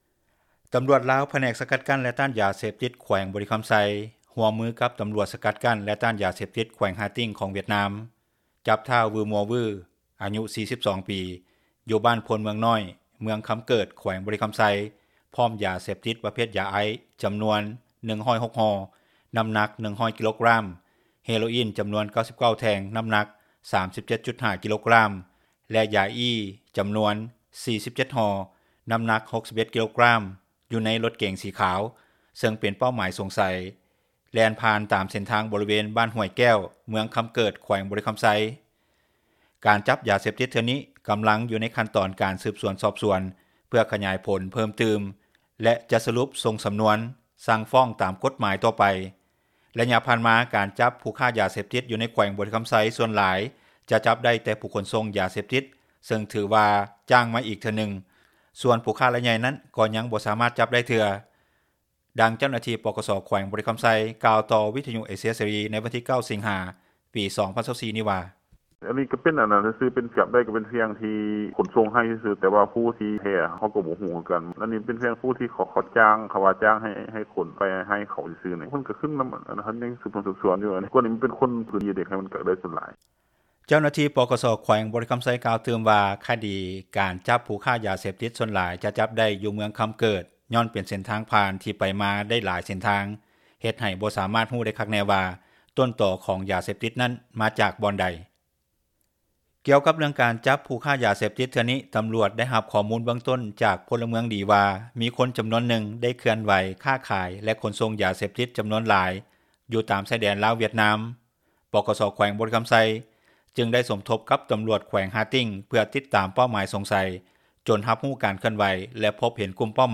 ແຕ່ທີ່ຜ່ານມາກໍ່ໄດ້ຍິນຂ່າວການຈັບຜູ້ຄ້າຢາເສບຕິດຢູ່ໃນເມືອງຄຳເກີດຫຼາຍເທື່ອຄືກັນ. ຢູ່ບໍລິເວັນເຂດບ້ານກໍ່ມີຕຳຫຼວດກໍ່ໄດ້ລົງວຽກຕັ້ງດ່ານກວດກາກ່ອນເຖີງເຖີງບ້ານຫຼັກ 20 ນໍາແຕ່ເມື່ອຂີ່ລົດກາຍໄປຈາກບ້ານຫຼັກ 20 ໄປທາງຊາຍແດນກໍບໍ່ມີຈຸດກວດກາແລ້ວ. ດັ່ງນາງກ່າວວ່າ.
ສໍາລັບໂທດຂອງຜູ້ກະທໍາຜິດໃນຄະດີນີ້ຜູ້ກ່ຽວອາດຖືກຕັດສີນໃຫ້ປະຫານຊີວິດຍ້ອນຂອງກາງຢາເສບຕິດທີ່ຢຶດໄດ້ມີຈຳນວນສູງເຖີງ 208 ກິໂລກຣາມ ແລະ ຂອງກາງເປັນຢາເສບຕິດທີ່ມີໂທດໜັກອີກຈຳນວນໜຶ່ງ. ຜູ້ກະທຳຜິດອາດຂໍຫຼຸດຜ່ອນໂທດໄດ້ເມື່ອຖືກຈຳຄຸກໄປແລ້ວໄລຍະໜຶ່ງ. ດັ່ງນັກກົດໝາຍທ່ານກ່າວວ່າ.